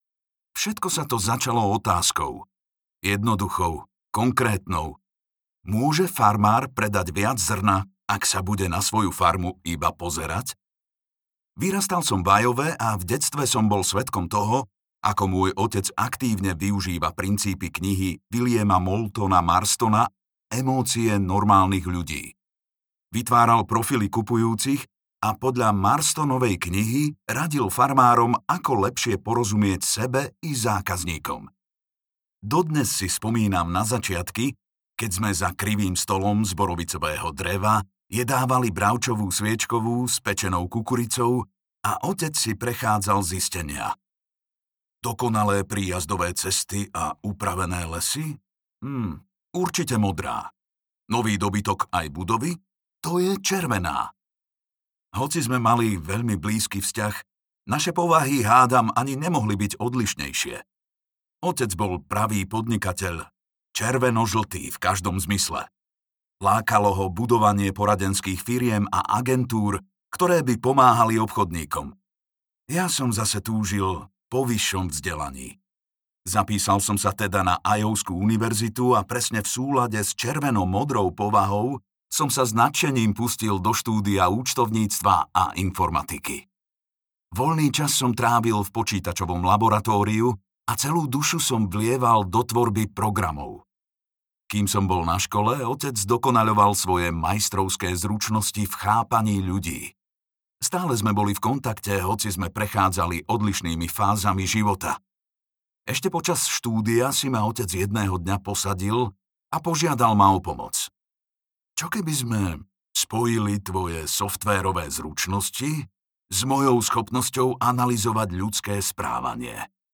Obklopený idiotmi audiokniha
Ukázka z knihy